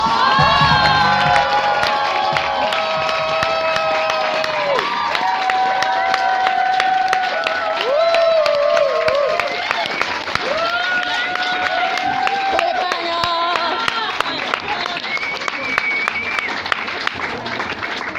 10 seconds of applause